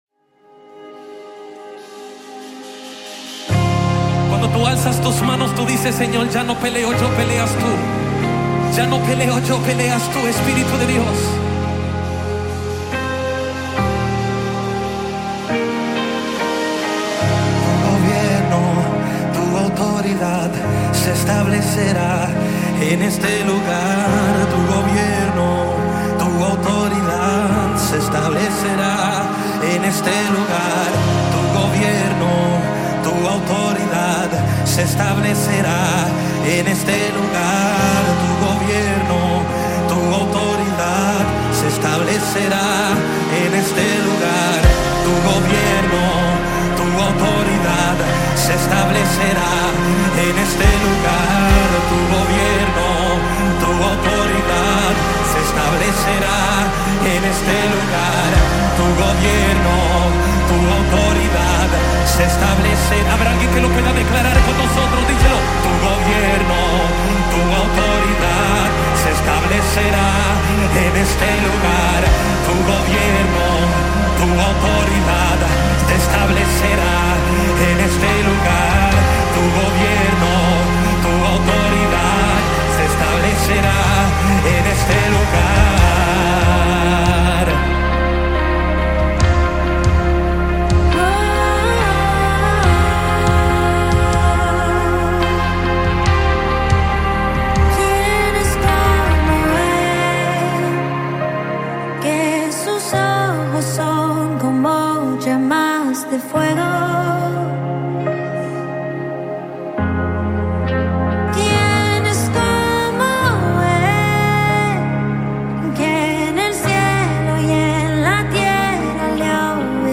BPM: 70